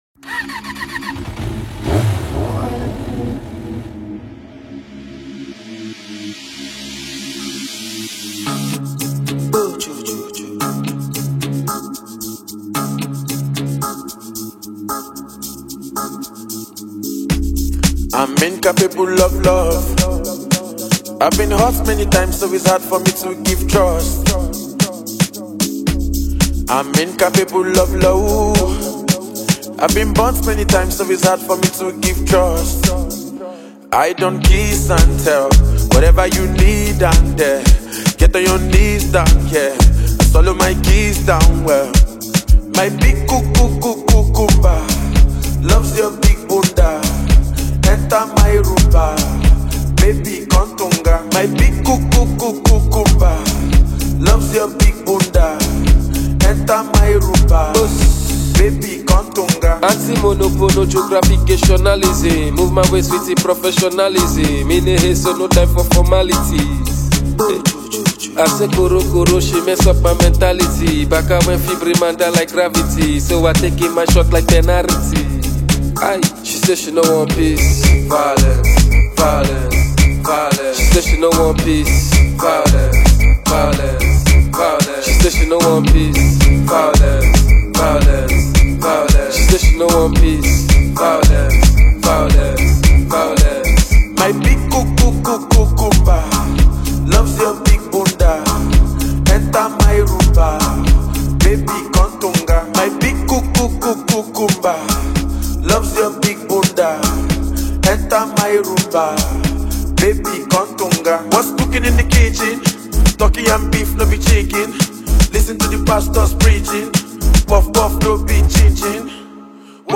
Afro-pop duo